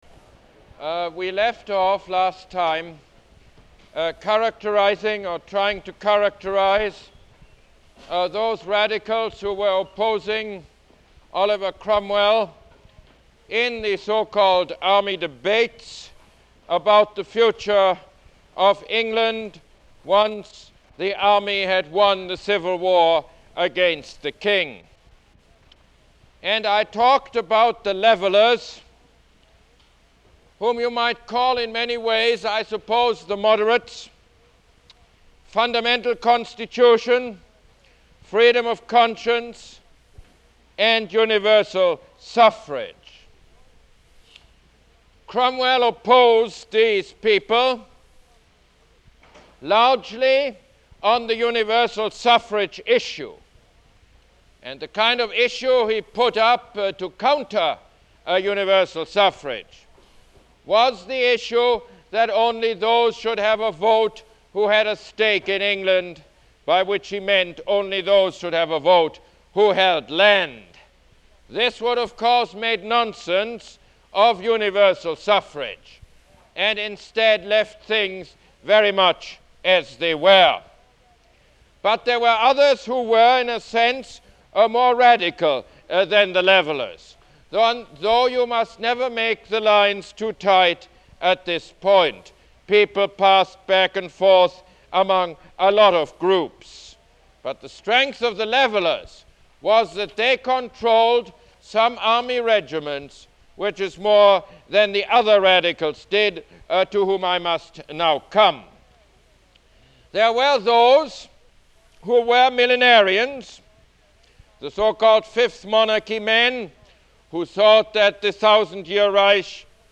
Lecture #16 - The English Revolution Part 2